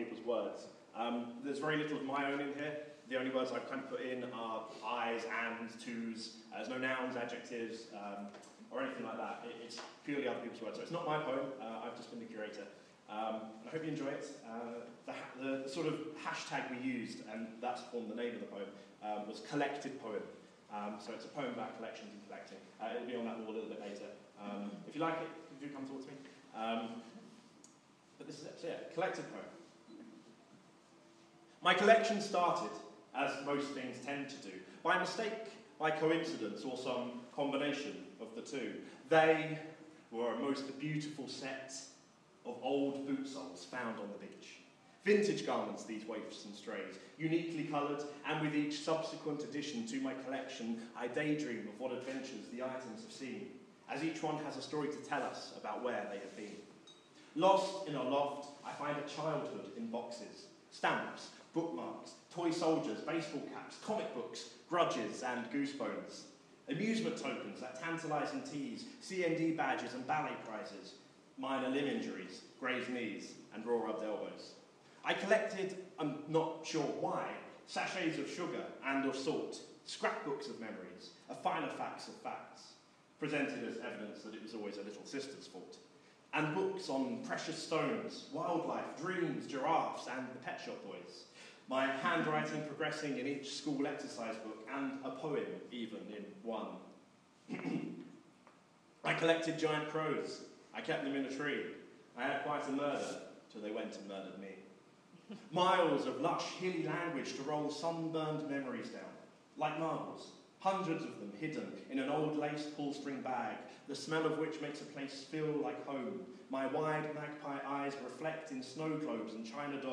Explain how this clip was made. This is a LIVE recording from the gallery.